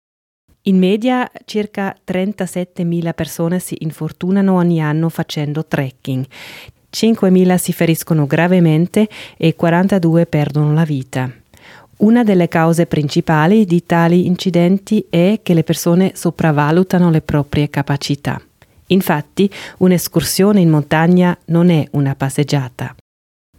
portavoce